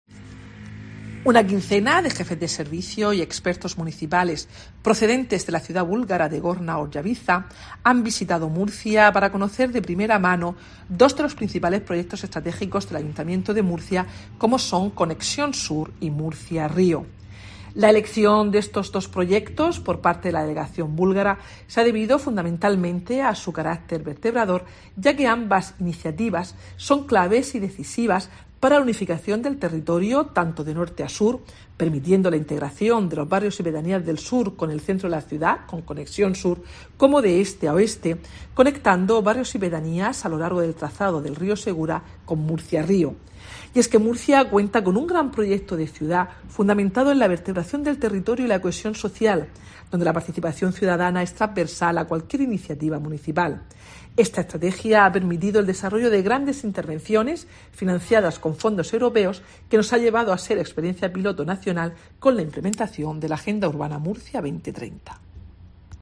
Mercedes Bernabé, concejal de Gobierno Abierto, Promoción Económica y Empleo